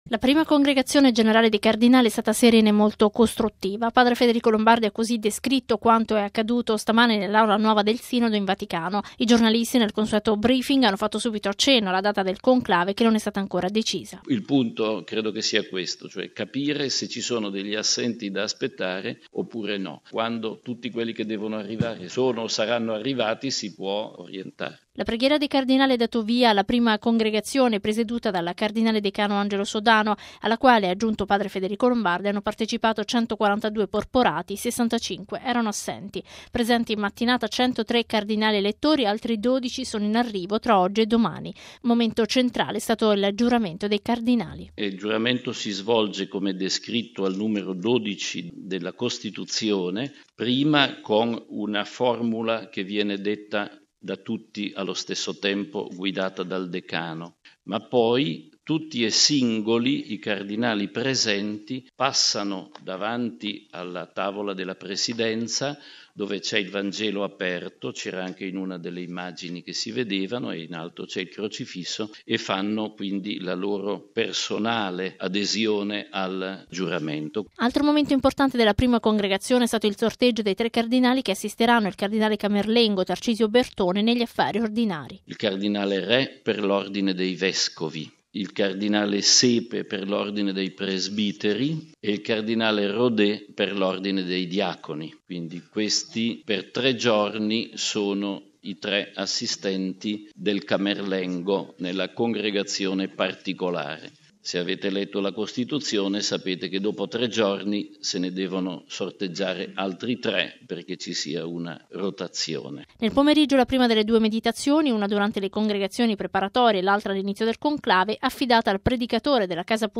◊   La data del Conclave si deciderà nei prossimi giorni. Così padre Federico Lombardi, direttore della Sala Stampa vaticana, nel corso del consueto briefing, riferendo della prima Congregazione generale del Collegio dei cardinali.